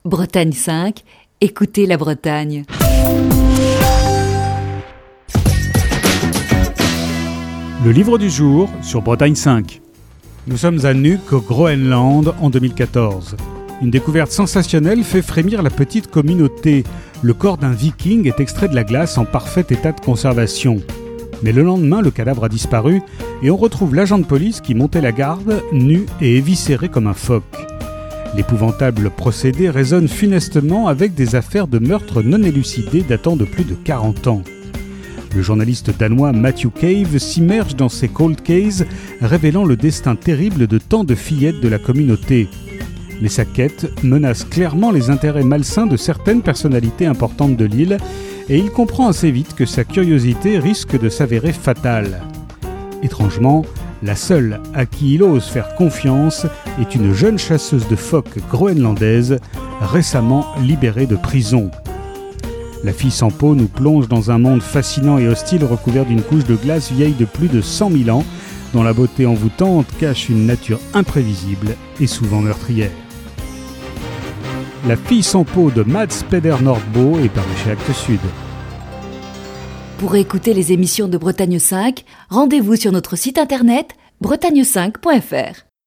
Chronique du 29 janvier 2020.